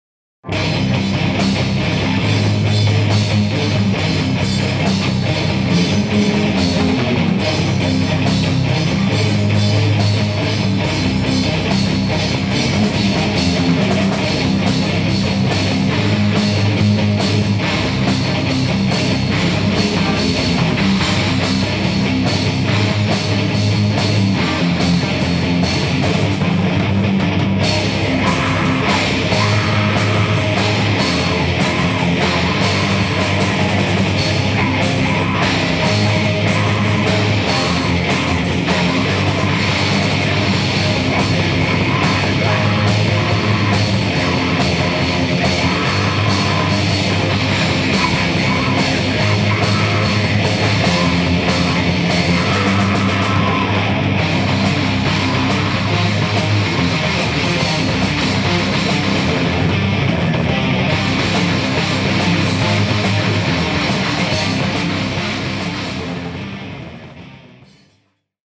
ZOOM H2 Digital Recorder – nagranie testowe 4-kanałowe (miks i normalizacja w komputerze)
4ch_zmiksowane_na_pc.mp3